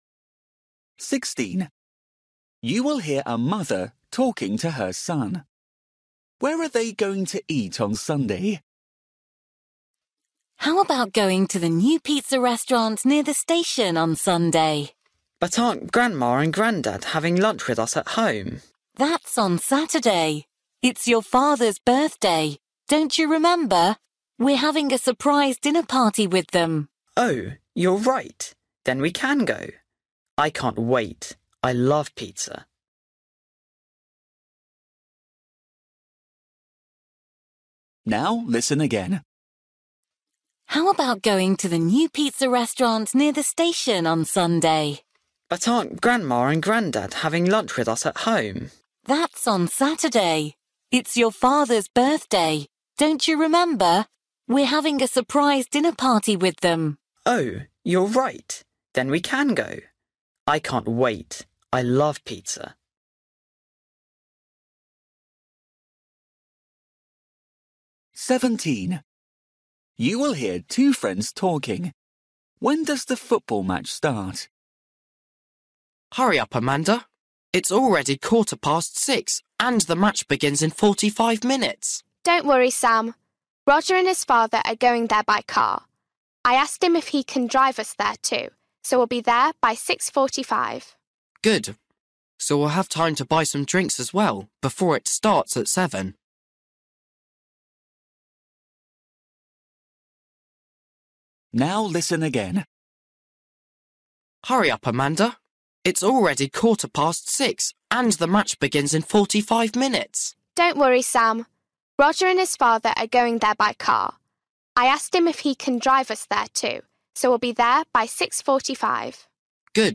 Listening: everyday short conversations
16   You will hear a mother talking to her son. Where are they going to eat on Sunday?
17   You will hear two friends talking. When does the football match start?
19   You will hear a man and a woman talking about buying a TV. What size do they want to buy?